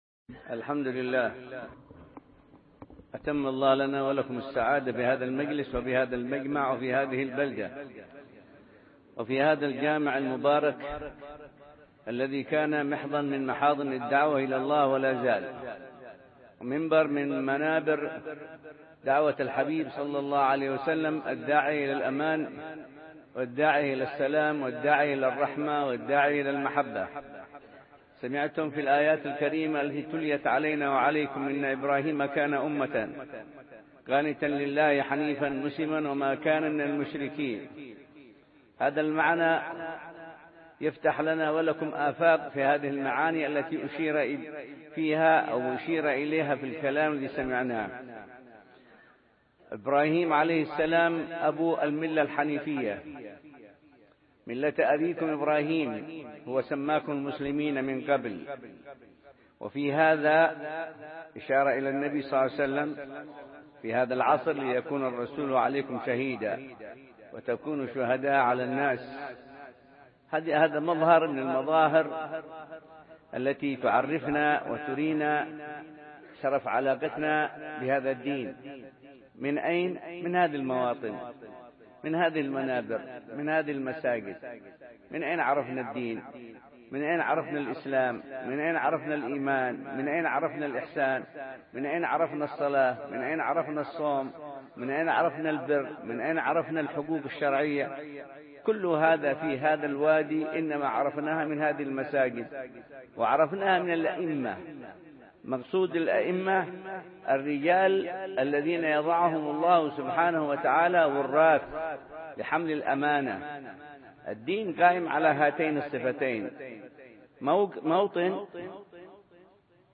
محاضرة
بجامع مدينة الغرفة بوادي حضرموت